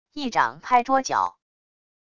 一掌拍桌角wav音频